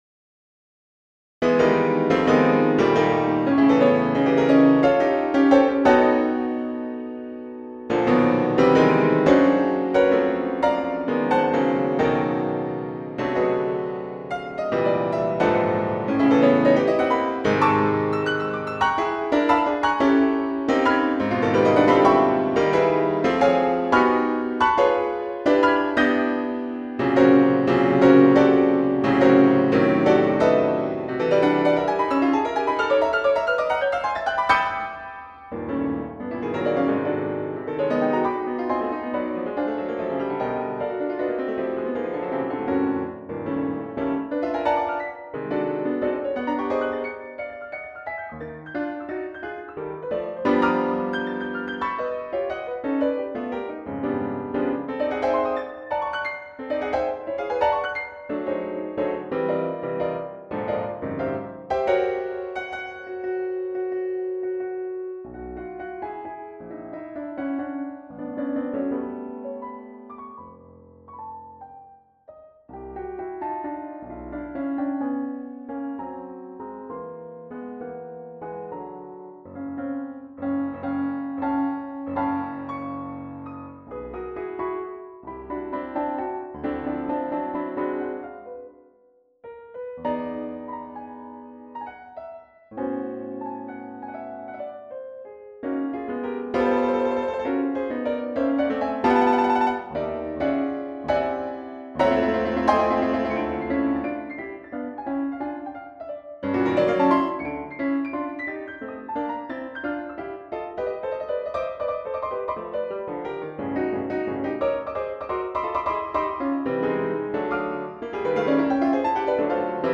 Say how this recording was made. Unperformed work, so just computer realisations (my apologies).